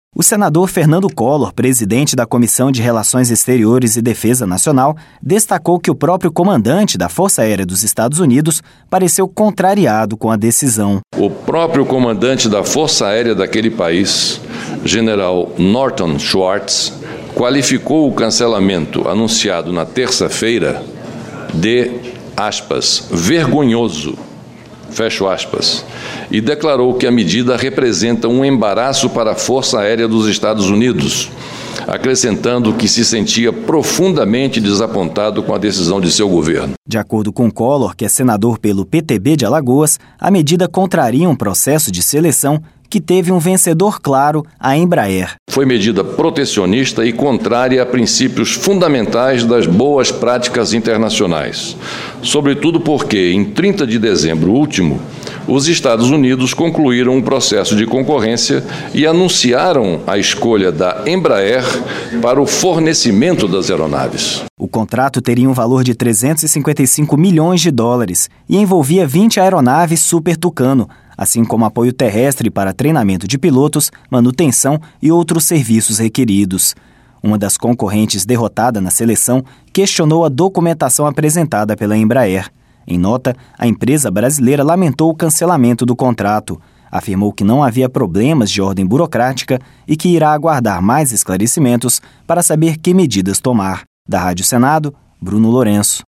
Rádio Senado
(REPÓRTER) O senador Fernando Collor, presidente da Comissão de Relações Exteriores e Defesa Nacional, destacou que o próprio comandante da Força Aérea dos Estados Unidos, pareceu contrariado com a decisão.